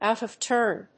アクセントòut of túrn